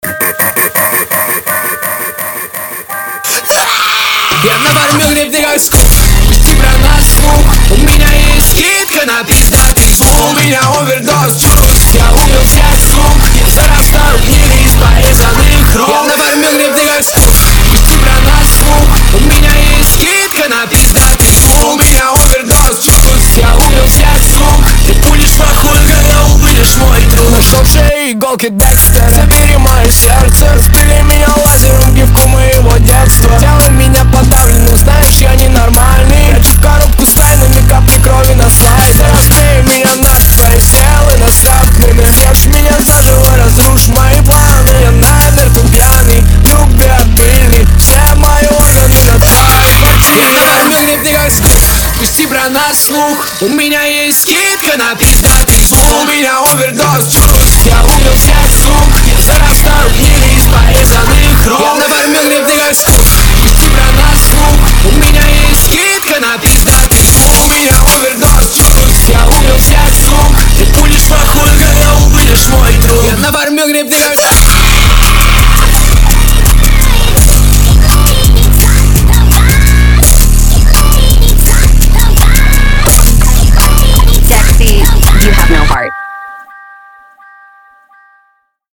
Поп музыка, Рэп